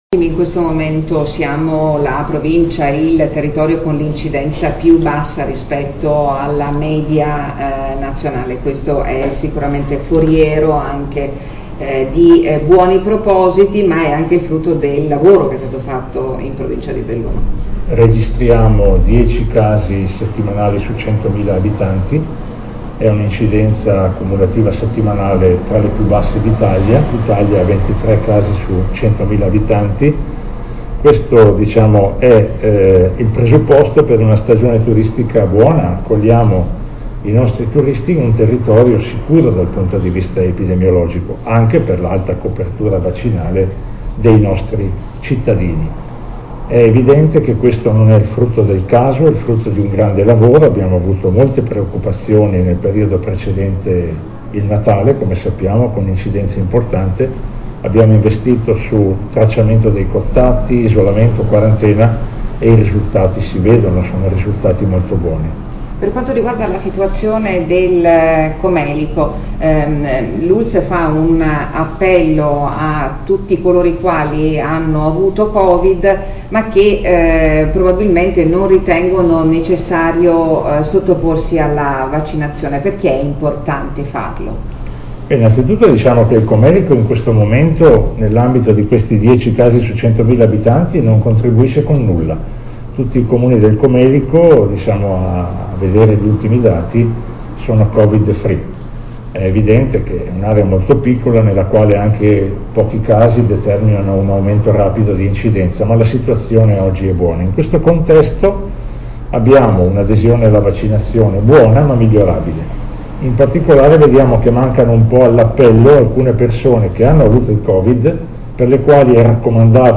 BELLUNO conferenza stampa per l’aggiornamento della campagna vaccinale